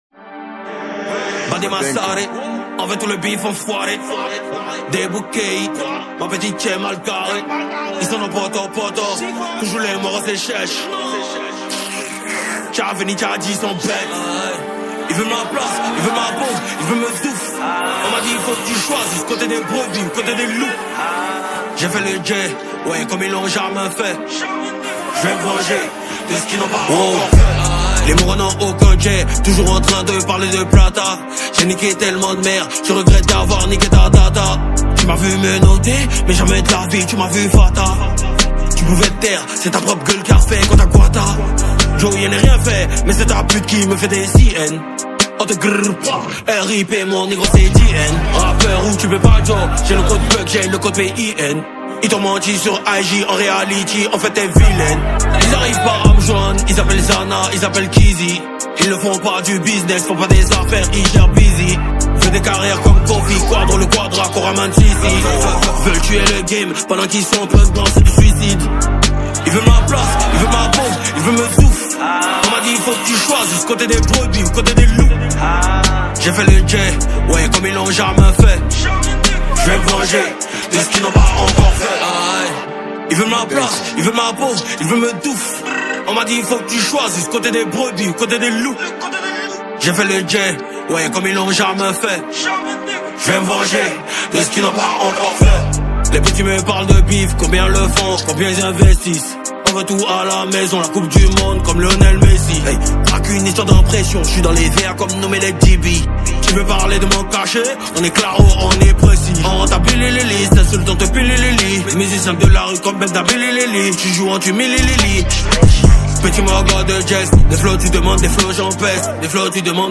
| Rap Hip-Hop